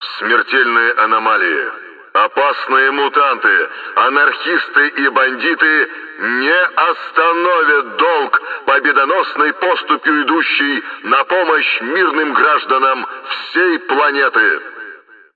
голосовые
из игры
громкоговоритель